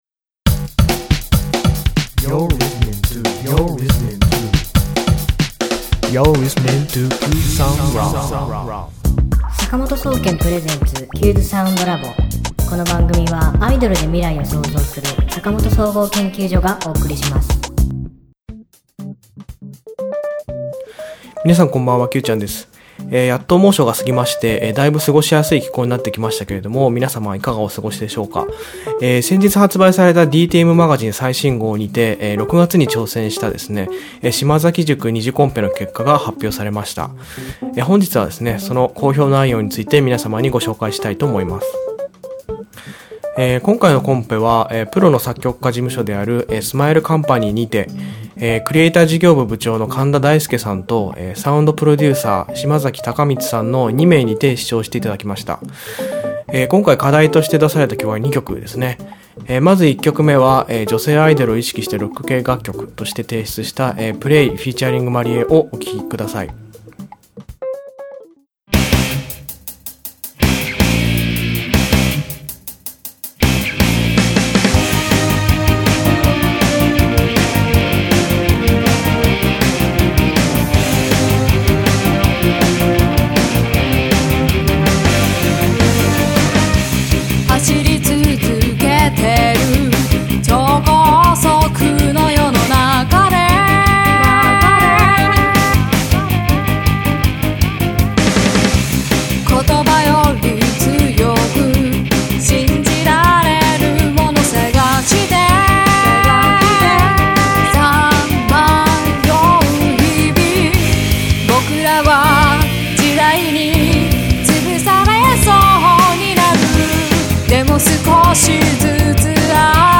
DTM MAGAZINEの投稿コーナー「島崎塾リターンズ」半年の連載で、デモ制作審査に選出された２名のスカウト候補に選ばれ、デモ制作審査に参加しました！その講評結果について、提出した２曲の楽曲とともに、たっぷりじっくりとご紹介したいと思いまーす。